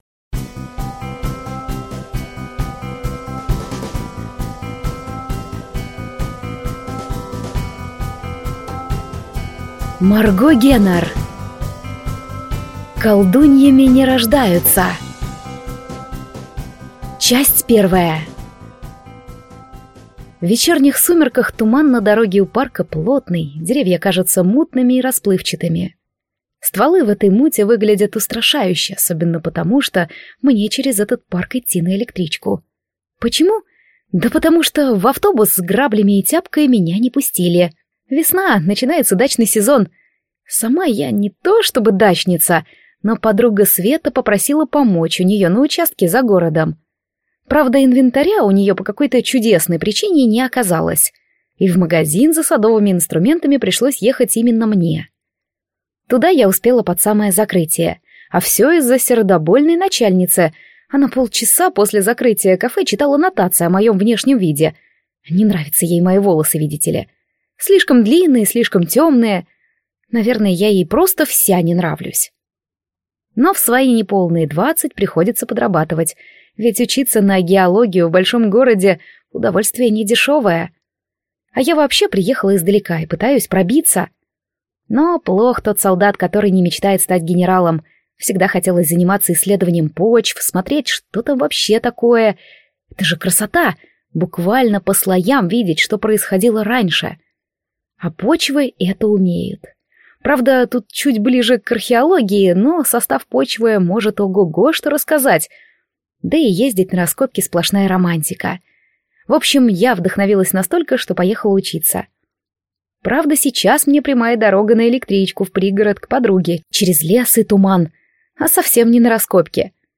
Аудиокнига Колдуньями не рождаются | Библиотека аудиокниг